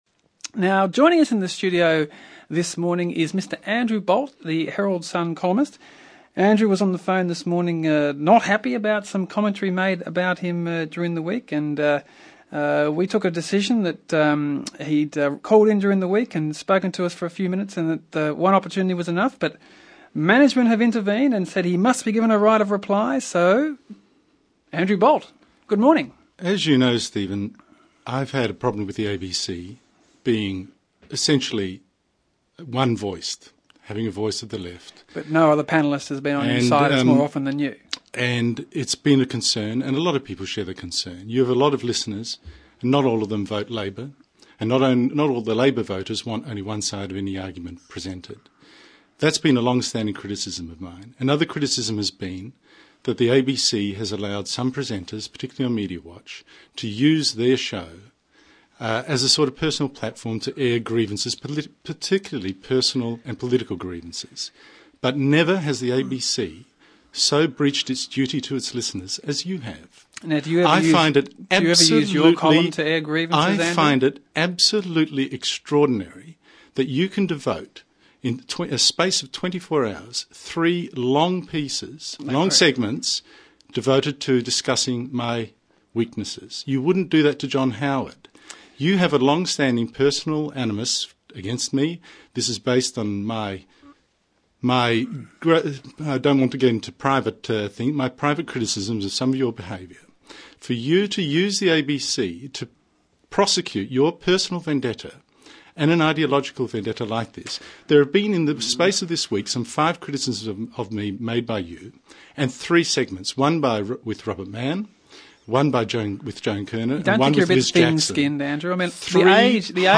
Back in 2005, Andrew Bolt came storming into the ABC studio for a major bust up with Stephen Mayne.